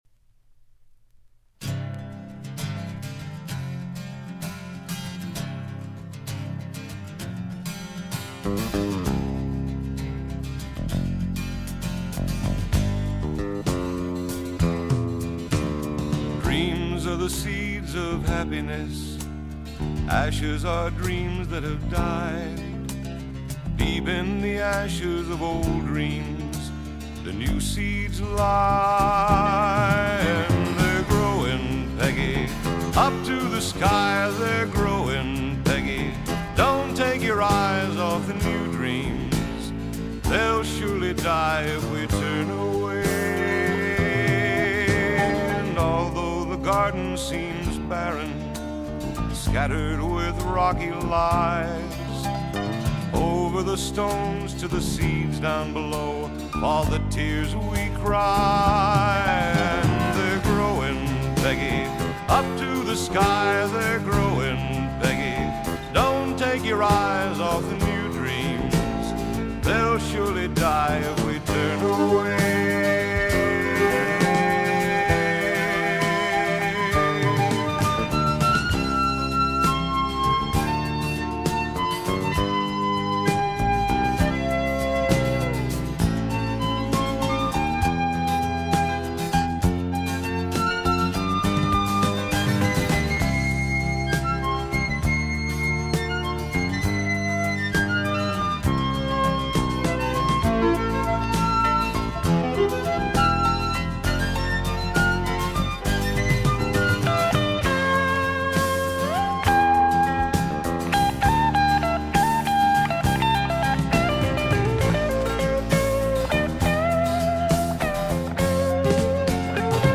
This folk music
(drums)
(piano)
(bass guitar)
(harmonica)
(banjo)
(strings)
(vocals)
Folk music--Iowa